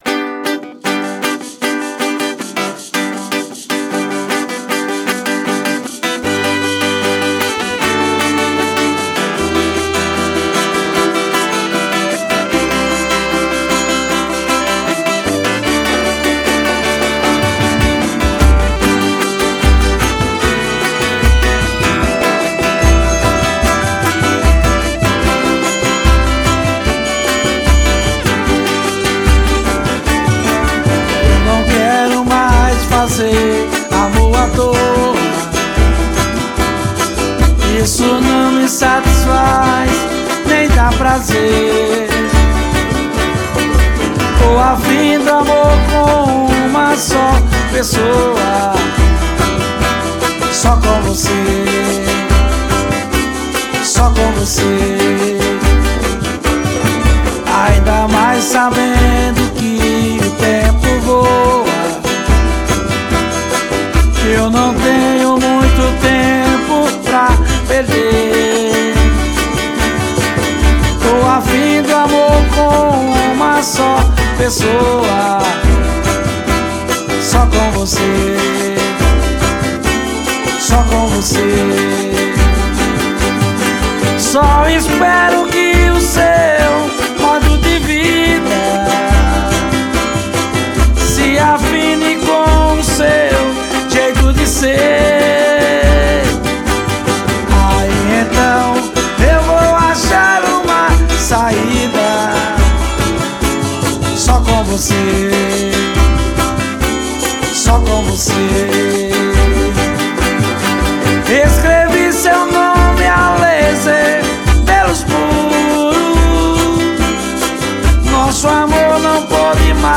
pagode sa.